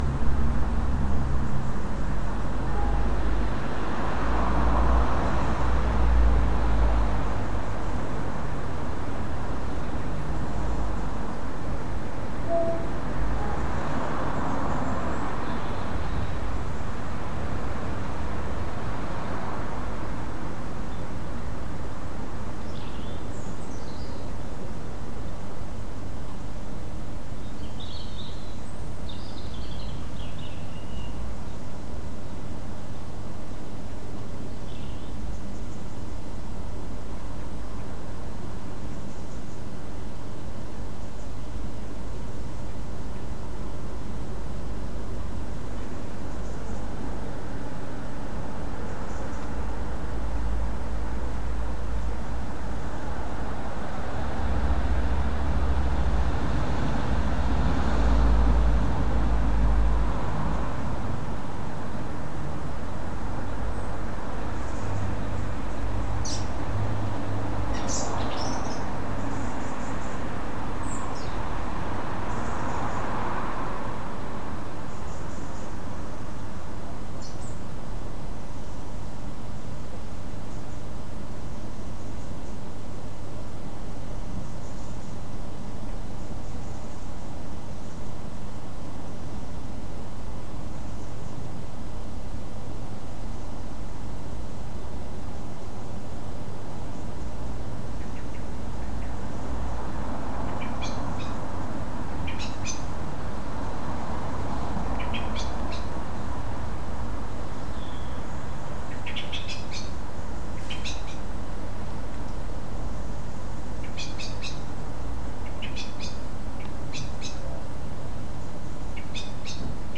Train, Traffic and Dusk Birds